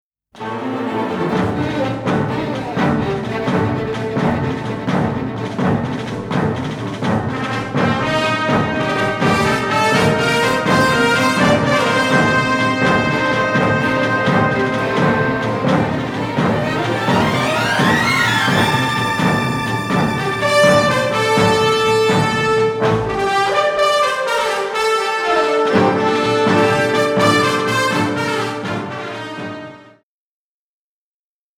striking western score
unedited 2-track stereo masters